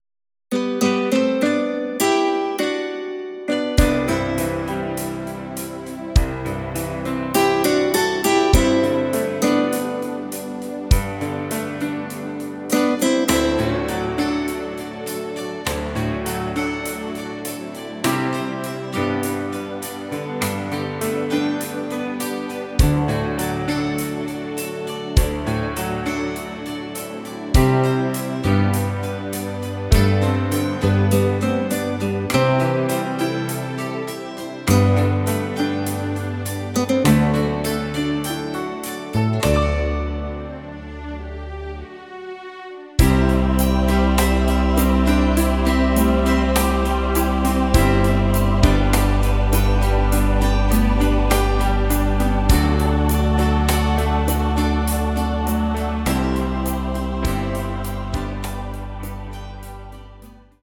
Rhythmus  Slow